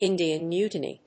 アクセントÍndian Mútiny